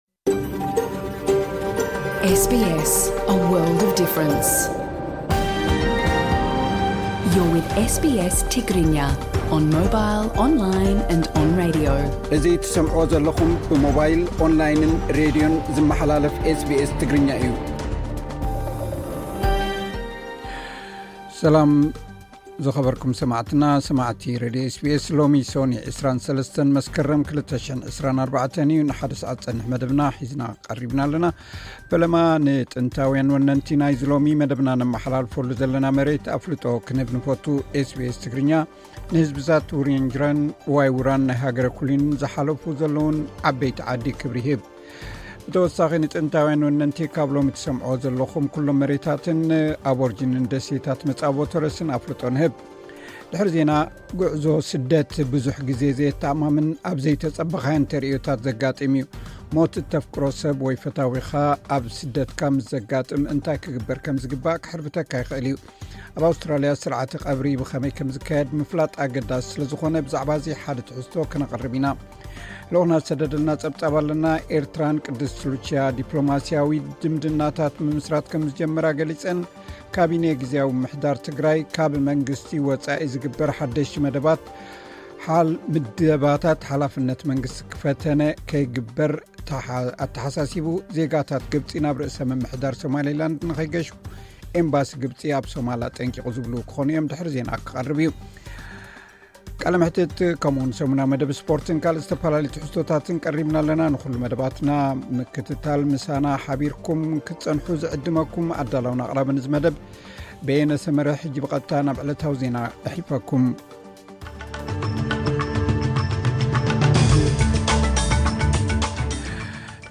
ቀጥታ ምሉእ ትሕዝቶ ኤስ ቢ ኤስ ትግርኛ (23 መስከረም 2024)